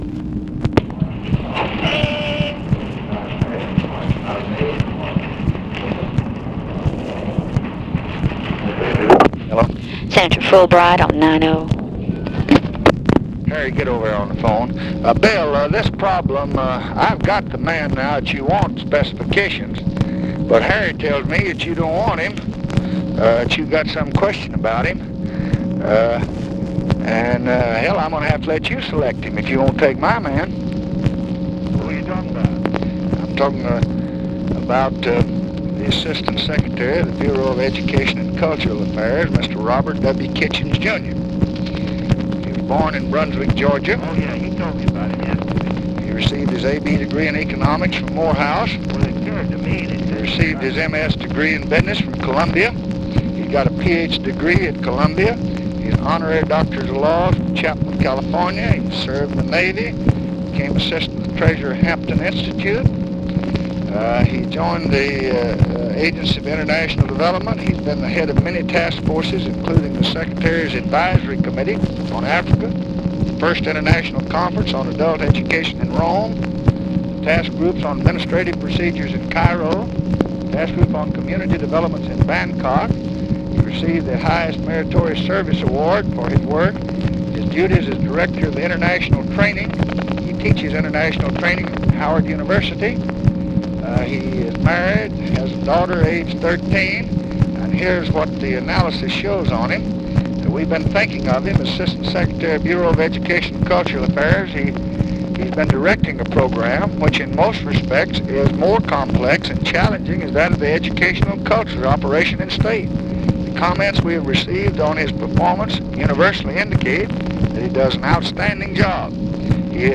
Conversation with WILLIAM FULBRIGHT and HARRY MCPHERSON, July 9, 1965
Secret White House Tapes